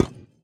Minecraft Version Minecraft Version snapshot Latest Release | Latest Snapshot snapshot / assets / minecraft / sounds / block / decorated_pot / insert_fail1.ogg Compare With Compare With Latest Release | Latest Snapshot
insert_fail1.ogg